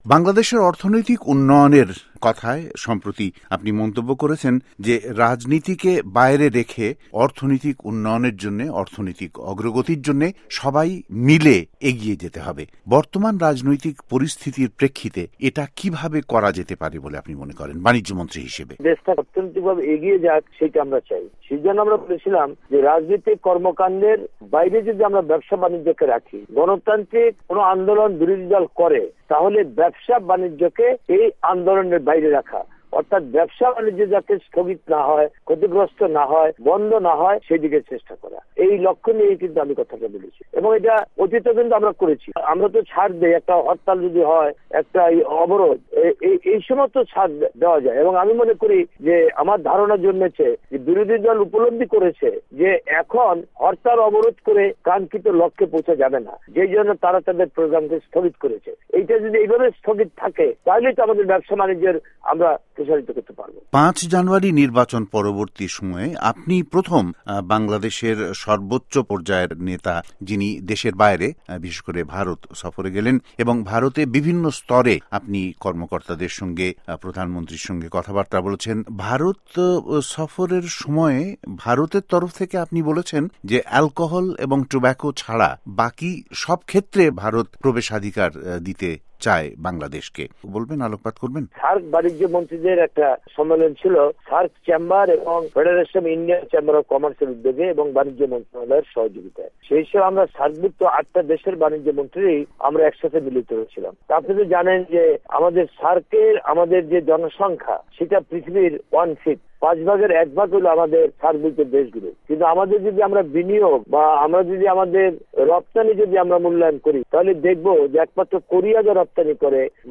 বাংলাদেশের অর্থনৈতিক উন্নয়ন ও বানিজ্য সম্প্রসারণ নিয়ে বানিজ্য মন্ত্রী তোফায়েল আহমদের সাক্ষাত্কার
tofael interview